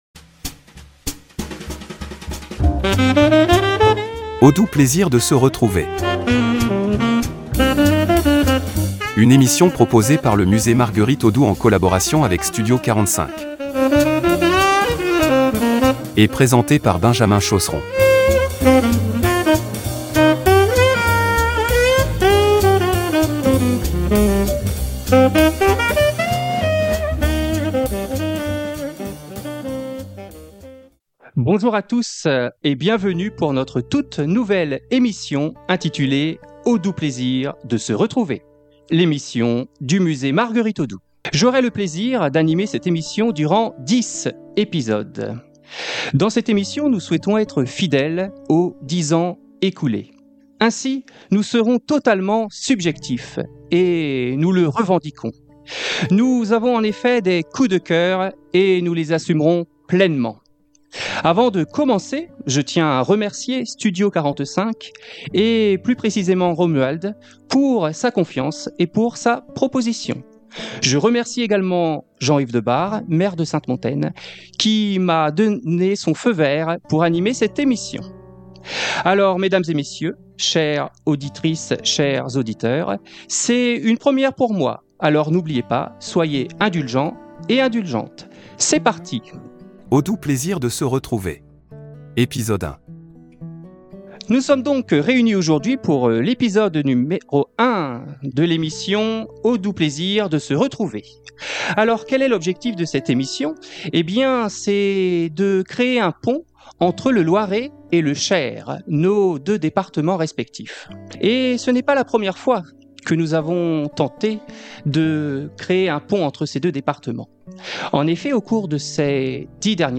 Pour inaugurer cette nouvelle émission enregistrée à Gien